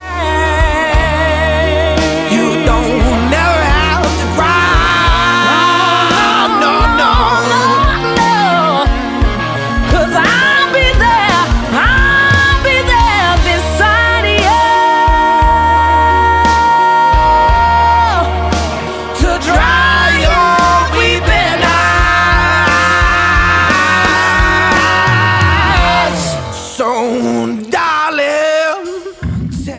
• Blues